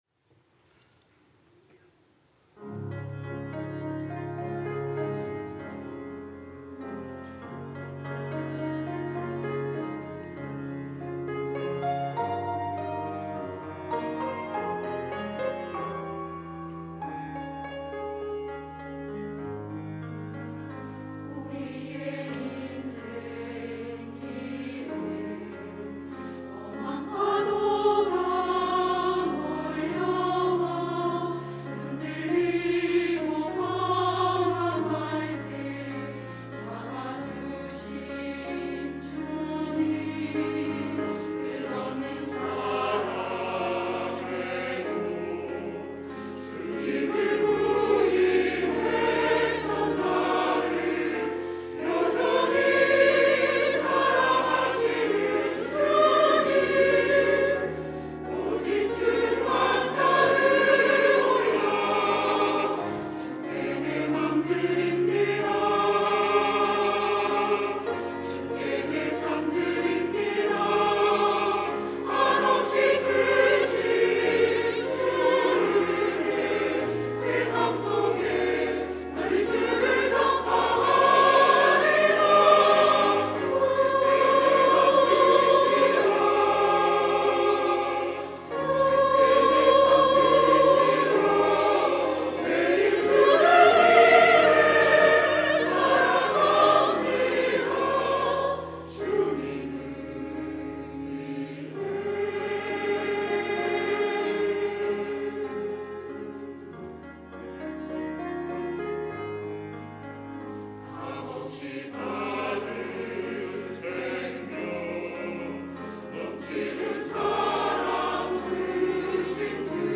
호산나(주일3부) - 주님을 위해
찬양대